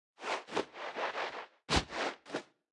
Media:Sfx_Anim_Ultimate_Medic.wav 动作音效 anim 在广场点击初级、经典、高手、顶尖和终极形态或者查看其技能时触发动作的音效
Sfx_Anim_Super_Medic.wav